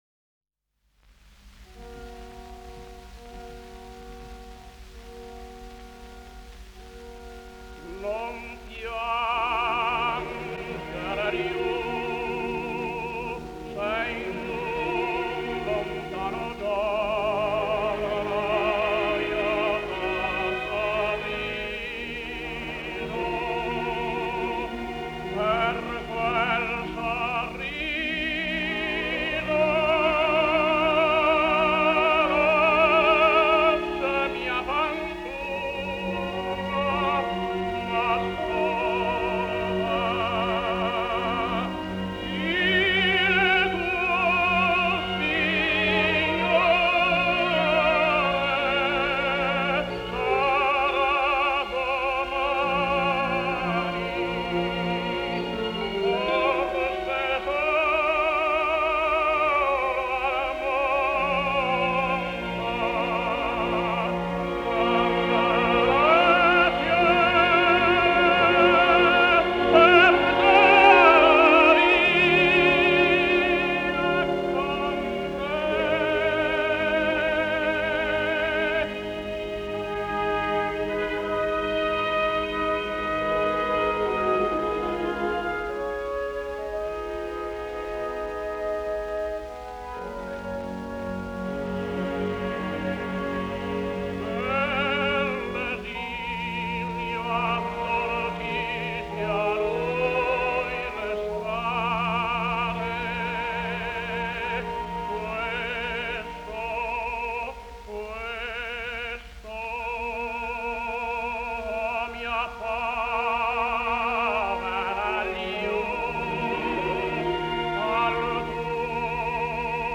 Italian Tenor.